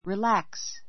relax 中 A2 rilǽks リ ら クス 動詞 （筋肉・規則などを） 緩 ゆる める , （気分などを） ゆったりさせる, くつろがせる; 緩む , くつろぐ, リラックスする relax the regulations relax the regulations 規則を緩める Listening to jazz relaxes me.